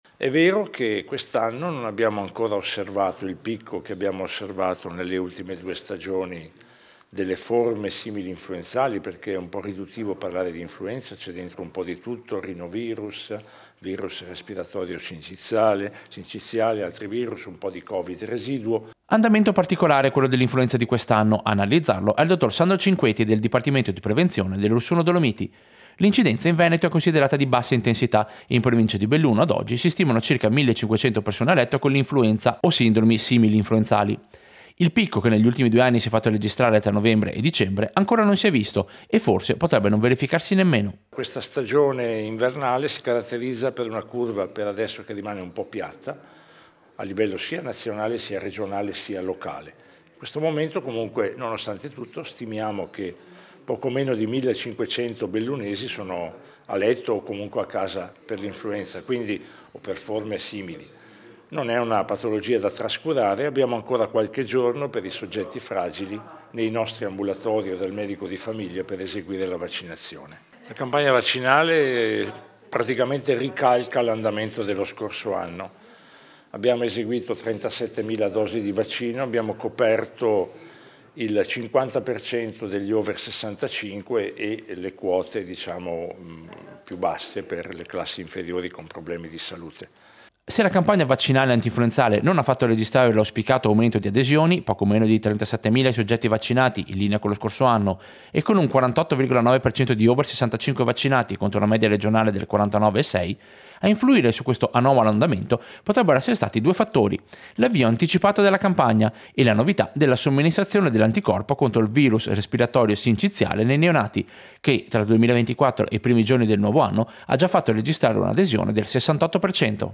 Servizio-influenza-Natale-24-25.mp3